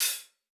TC2 Live Hihat11.wav